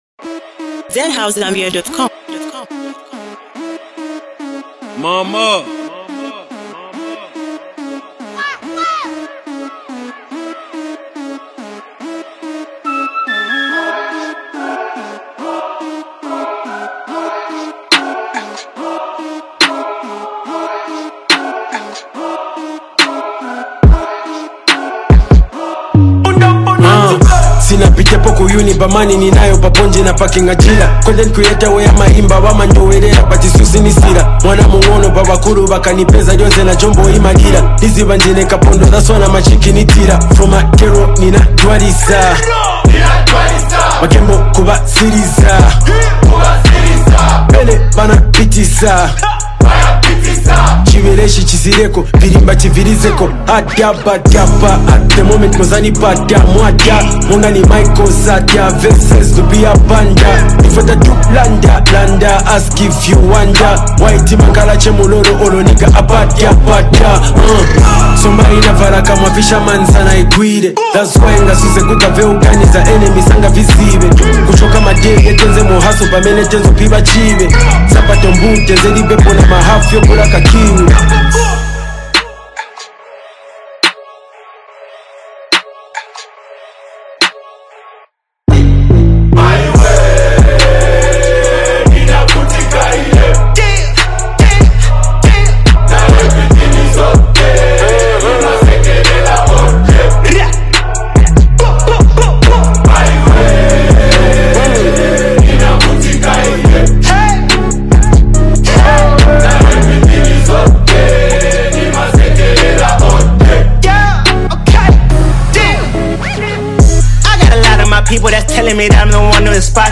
deeply emotional track